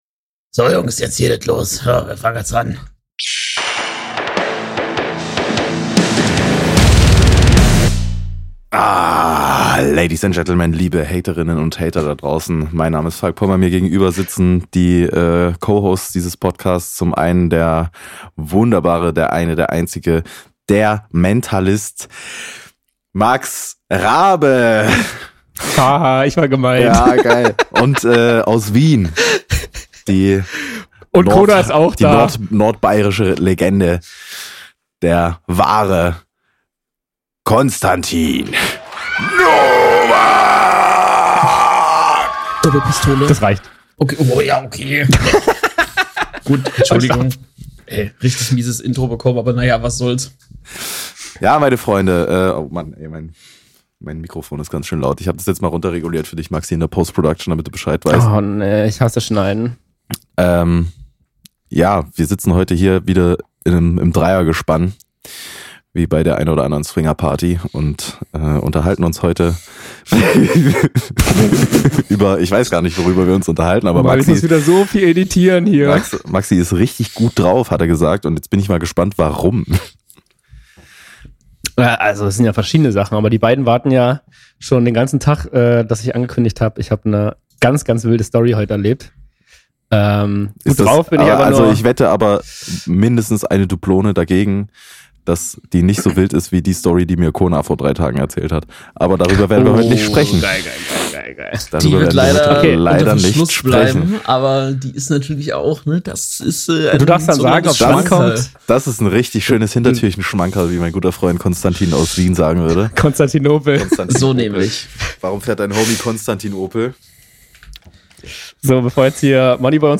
Heute wieder euer dynamisches Dreiergespann am Start!